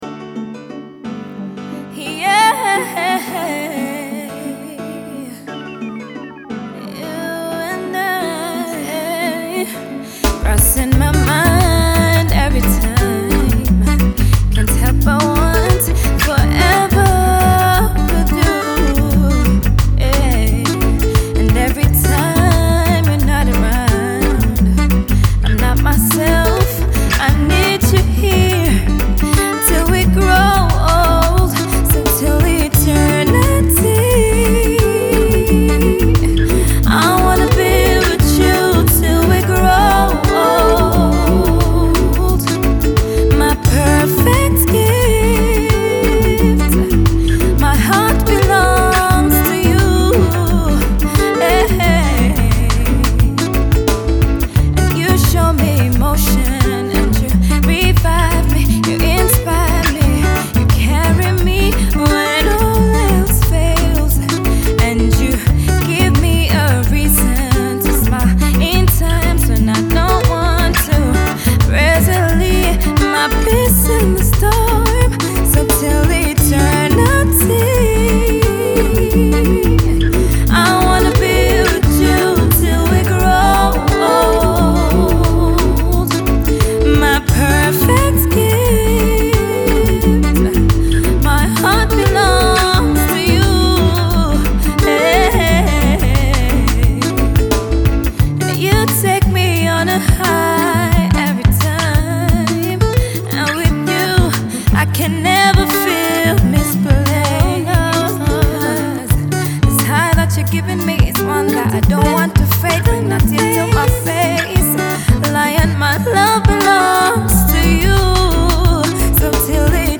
Genre: Reggae Dancehall